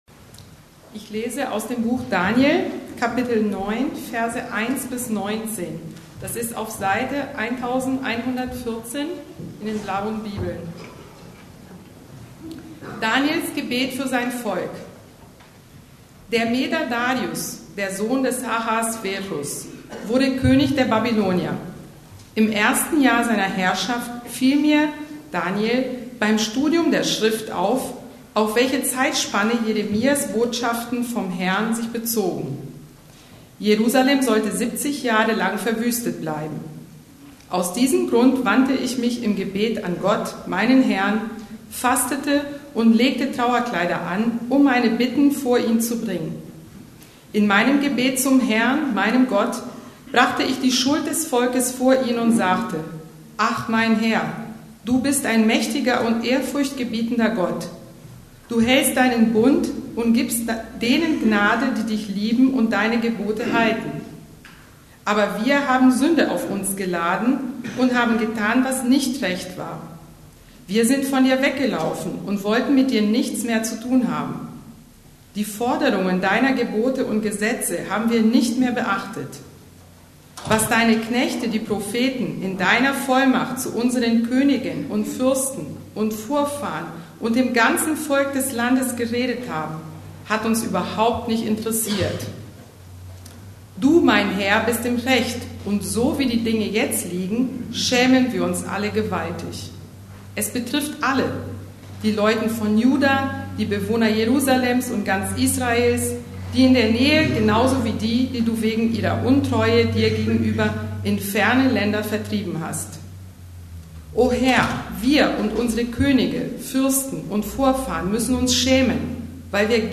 Heilige Leidenschaften Teil 2: Daniel ~ Predigten der LUKAS GEMEINDE Podcast